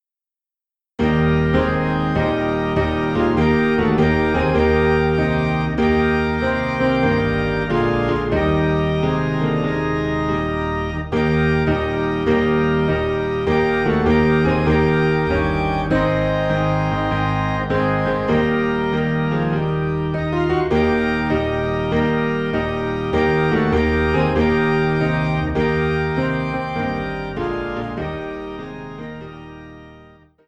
PIANO AND ORGAN DUET SERIES